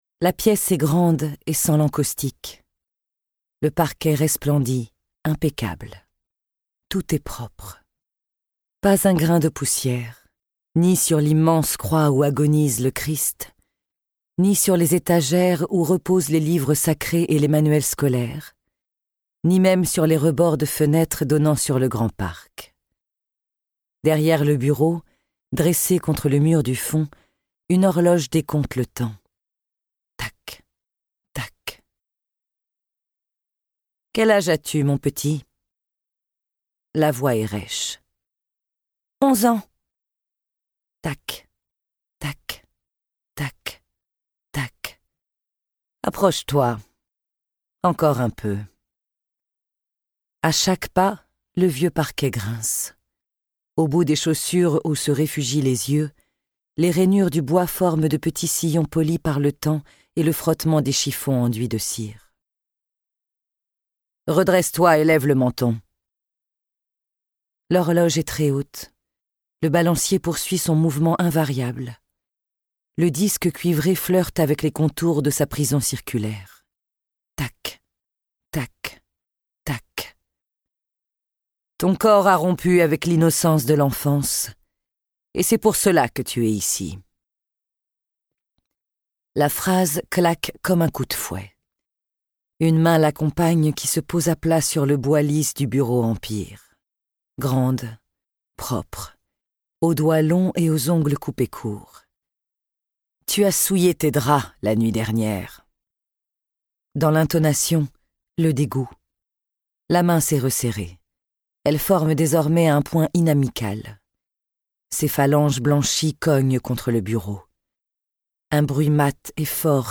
Click for an excerpt - Châtiment de Céline DENJEAN
ce livre audio vous fera découvrir des secrets de famille qui auraient peut-être mieux faits de rester enfouis...